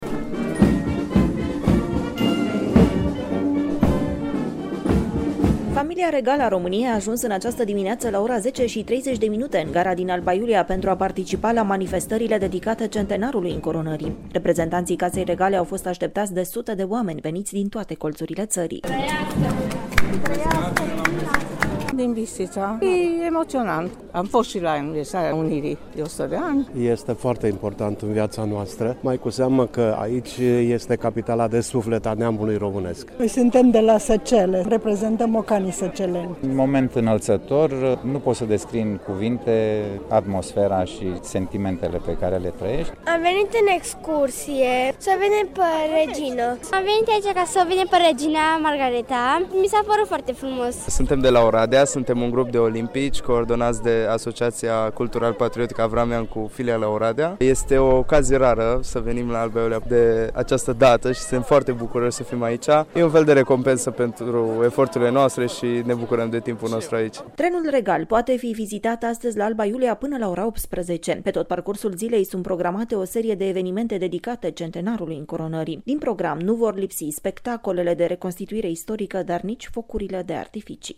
Este atmosferă de sărbătoare, astăzi, la Alba Iulia, când se împlinesc 100 de ani de la încoronarea regelui Ferdinand I şi a reginei Maria.
Corespondenta Radio România